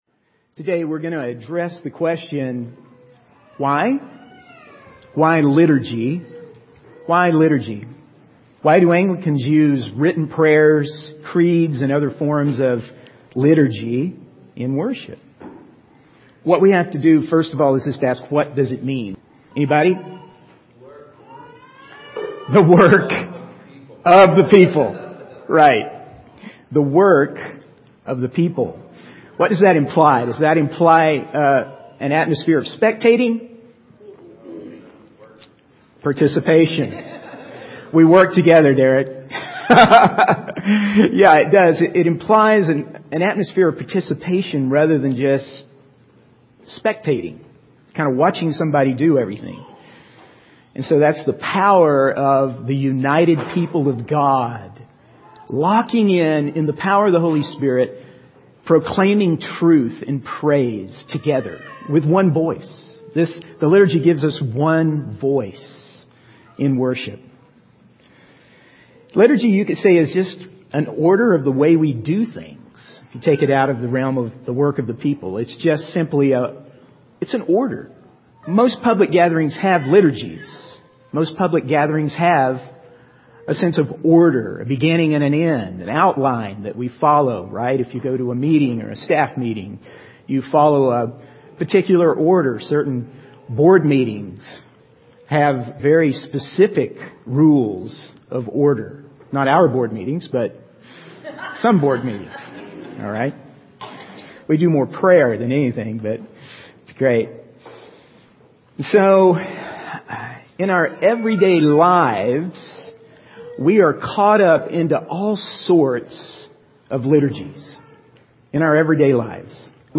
In this sermon, the preacher emphasizes the importance of embracing the process of life, including the mundane and trying times.